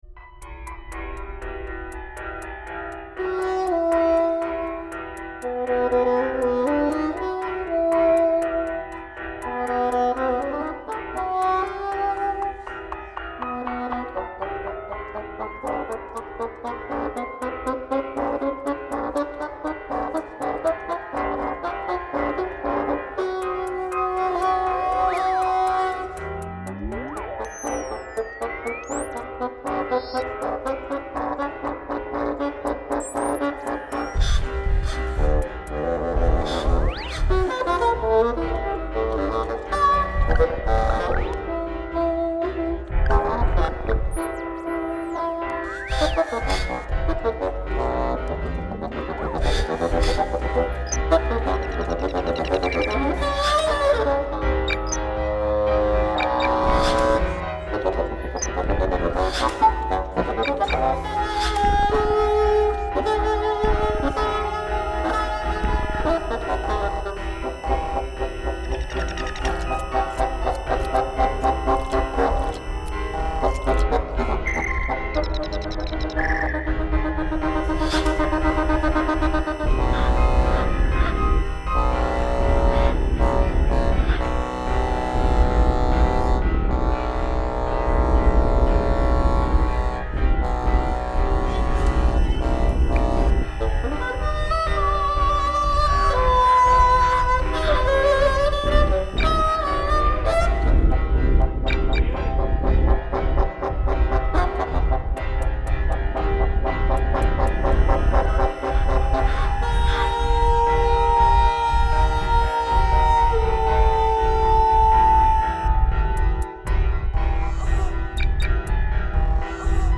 für Fagott und Tonband